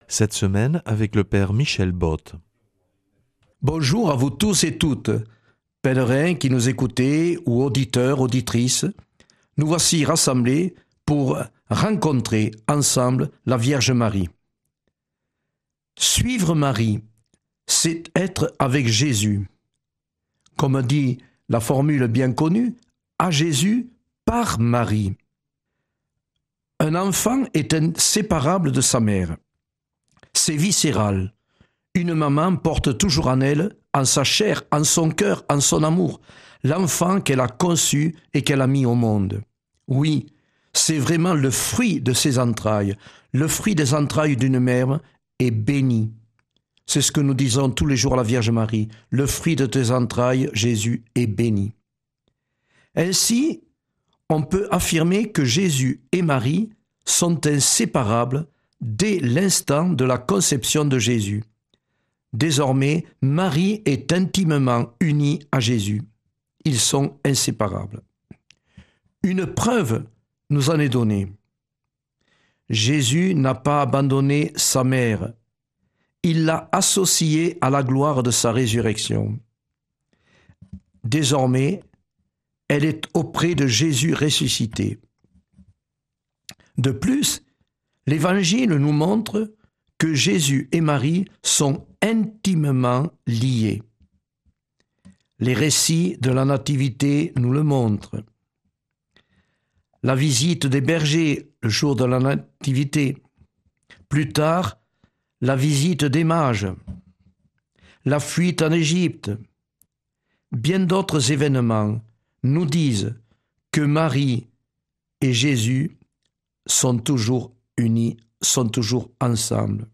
lundi 2 février 2026 Enseignement Marial Durée 10 min